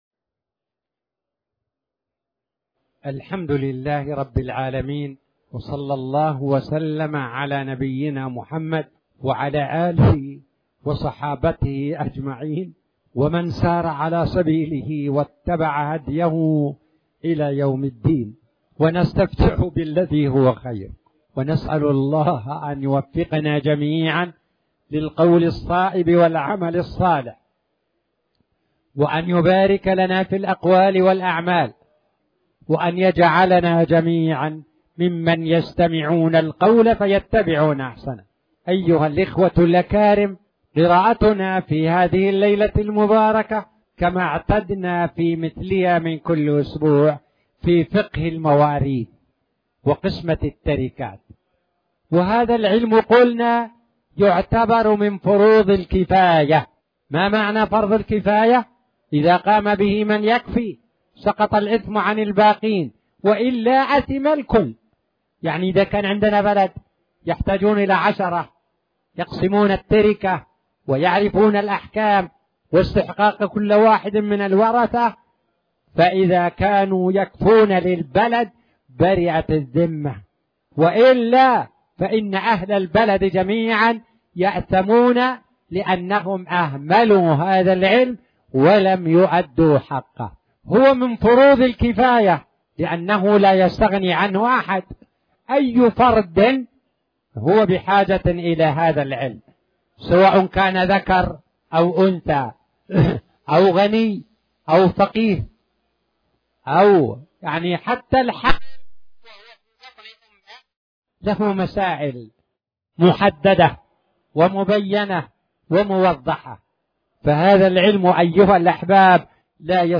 تاريخ النشر ٩ جمادى الأولى ١٤٣٨ هـ المكان: المسجد الحرام الشيخ